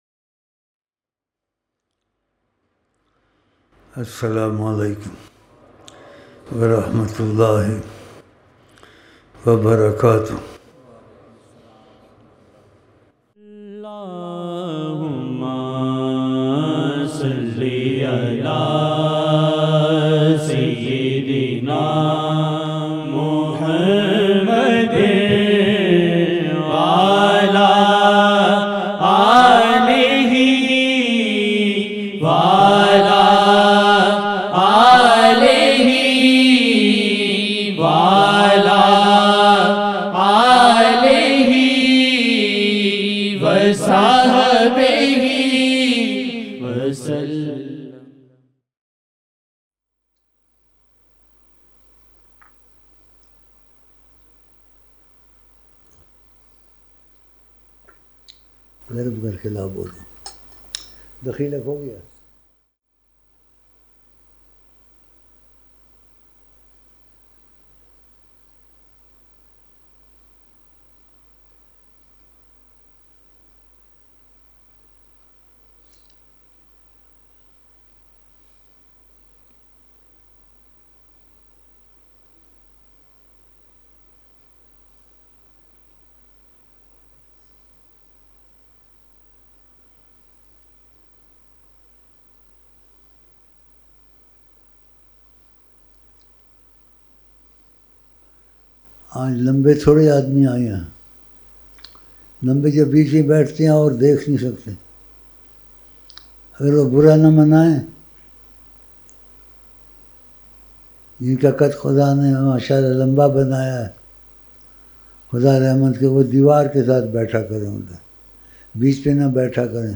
13-Aug-2019-Eid-Ul-Adha-Tahajud-12-Zil-Hajj-1440-Mehfil-Important-Tarbiyat.Part-2